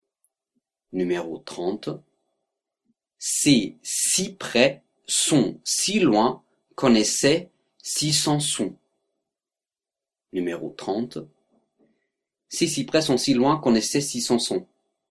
30 Virelangue